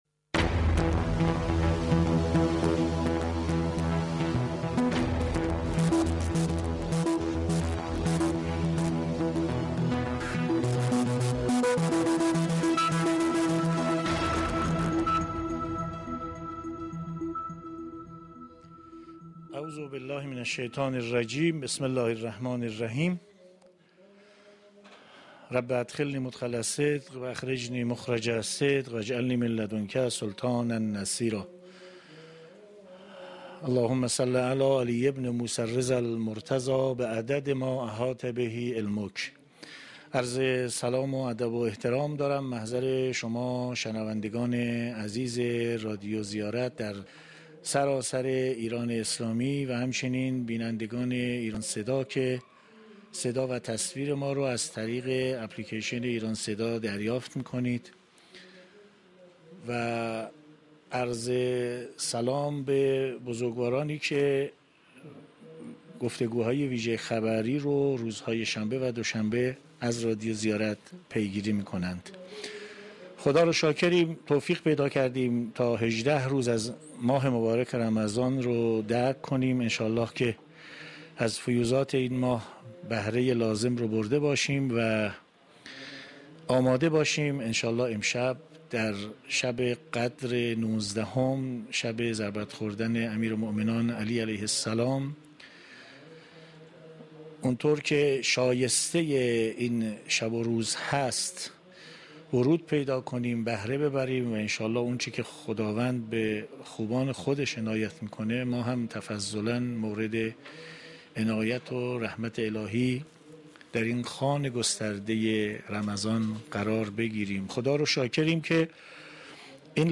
در گفتگوی ویژه خبری رادیو زیارت که از استودیوی رادیو در حرم رضوی پخش می شد